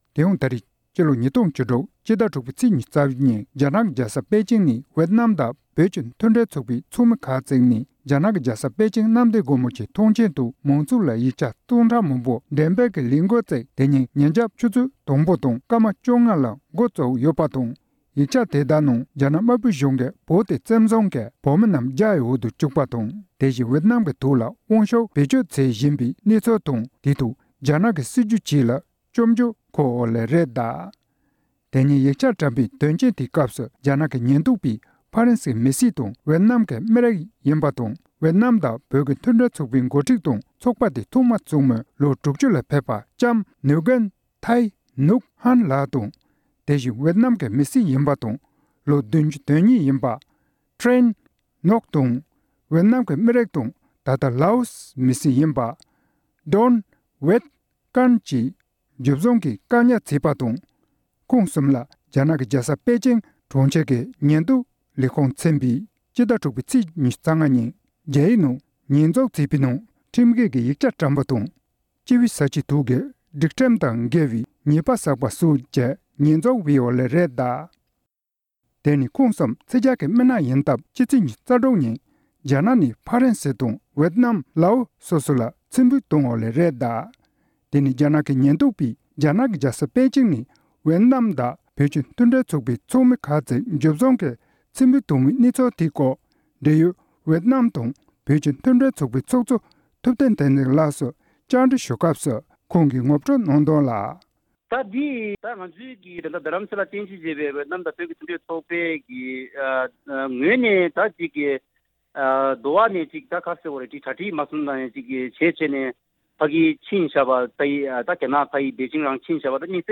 བཅར་འདྲི་ཞུས་ནས་ཕྱོགས་བསྒྲིགས་དང་སྙན་སྒྲོན་ཞུས་པར་གསན་རོགས་ཞུ༎༔